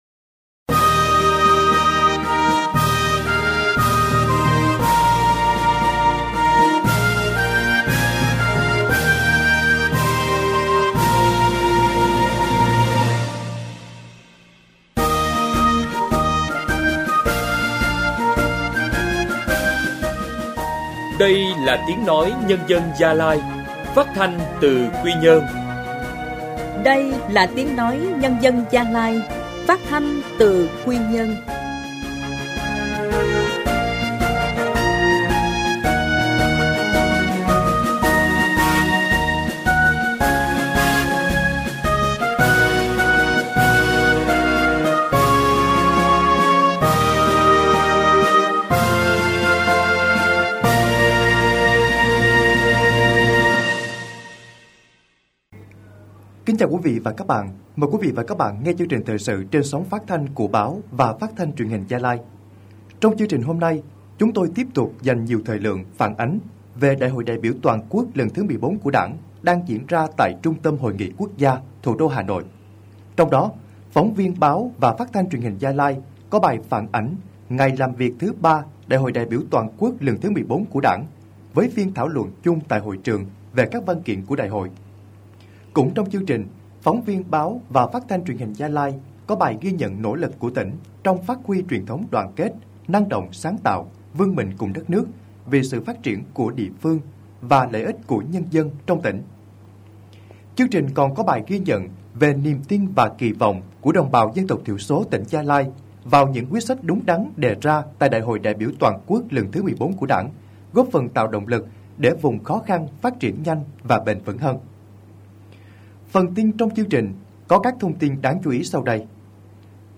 Thời sự phát thanh tối